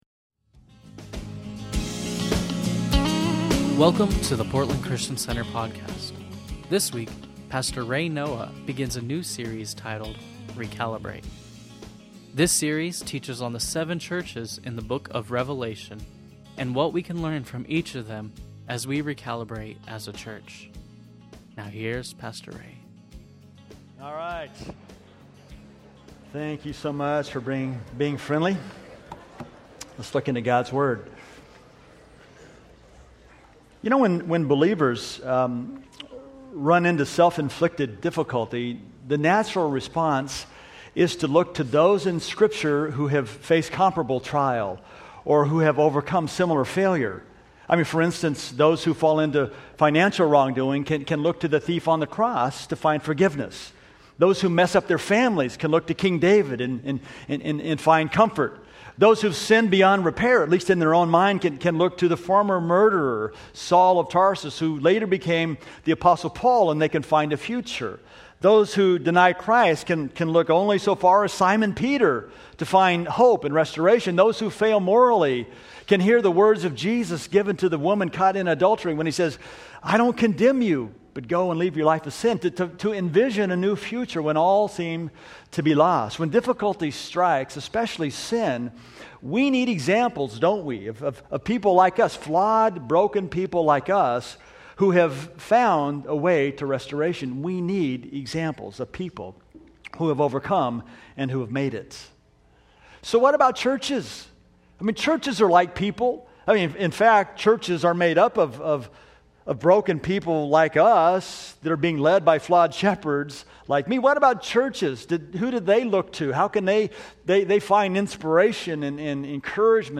Sunday Messages from Portland Christian Center Jesus & PCC: A Call To Recalibrate Oct 12 2014 | 00:41:18 1x 00:00 / 00:41:18 Subscribe Share Spotify RSS Feed Share Link Embed